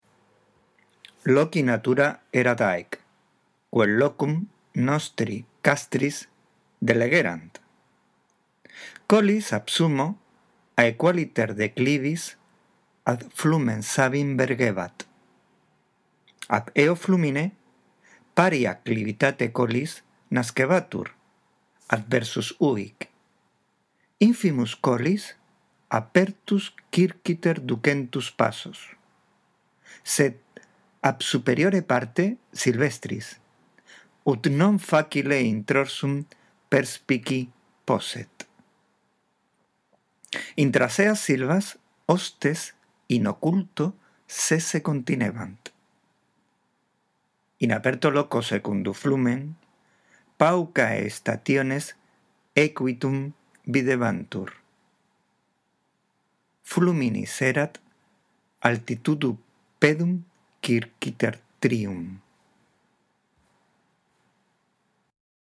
Esta audición del texto te guiará en tu práctica de lectura
Tienes bajo el mismo texto una lectura que puede orientarte.